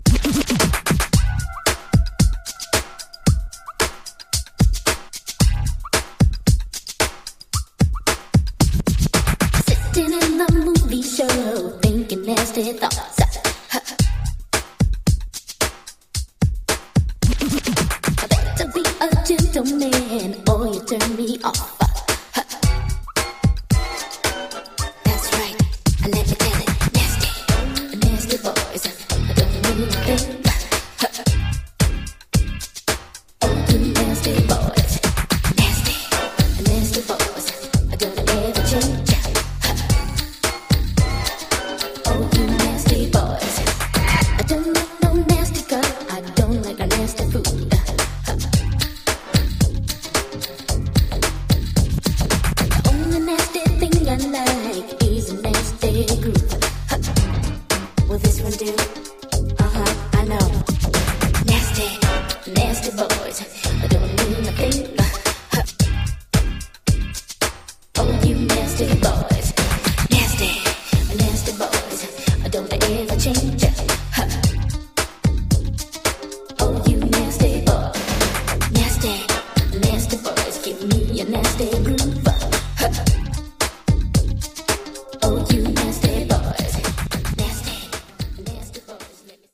112 bpm